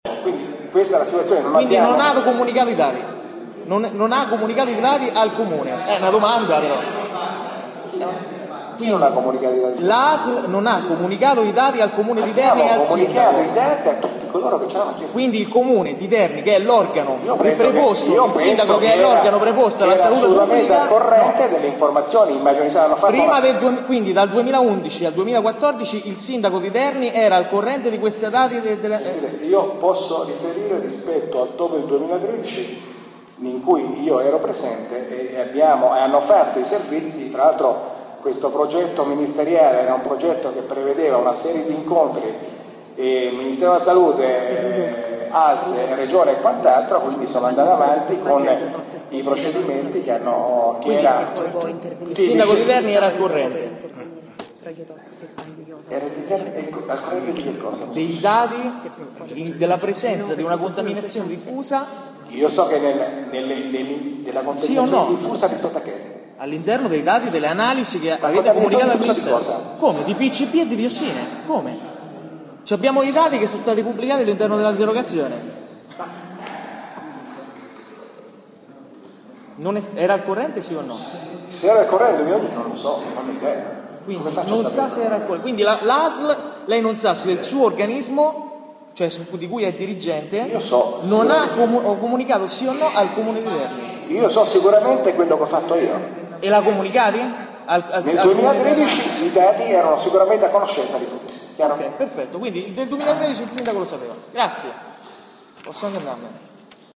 una in particolare – del direttore generale dell’Usl Umbria2, Sandro Fratini, nell’audizione di giovedì 20 novembre davanti alla seconda commissione consiliare del comune di Terni (ASCOLTA IL FILE AUDIO). Incalzato da Thomas De Luca (M5S), Fratini alla fine ha spiegato che «nel 2013, tutti erano a conoscenza dei dati».